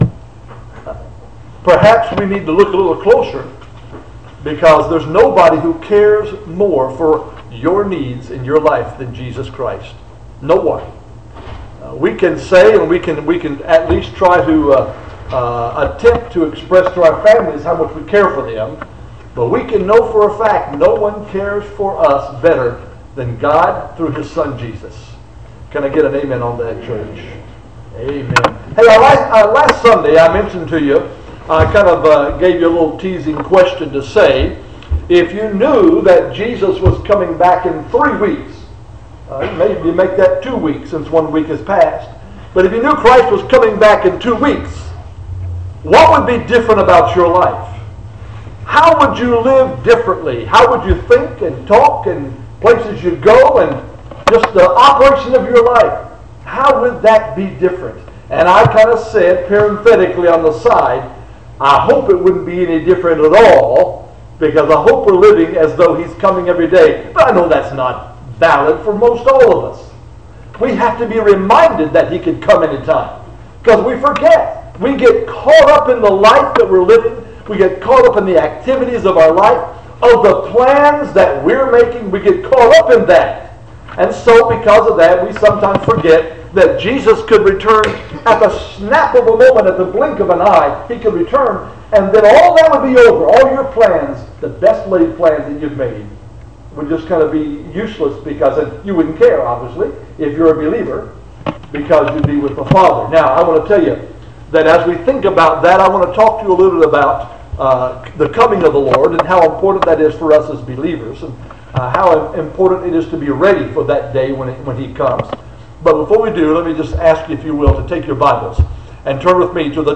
Sermons - Jackson Ridge Baptist Church